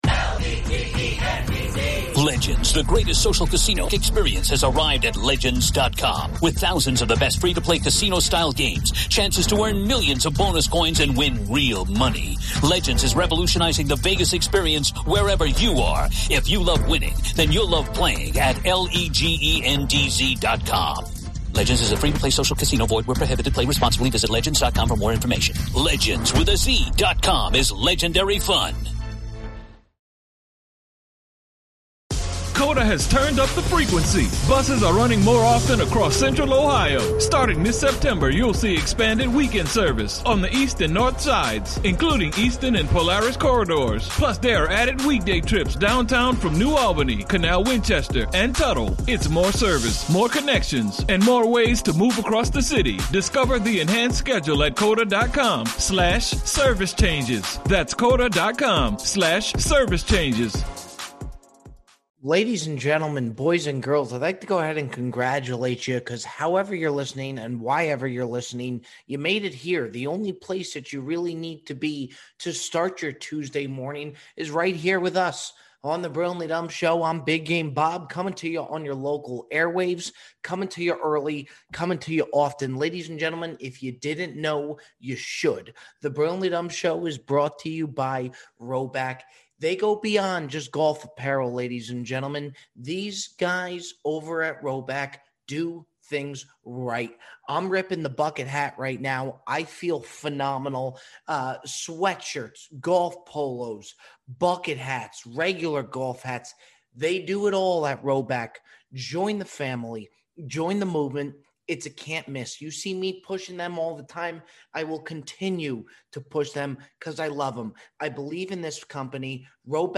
Voicemails close the show.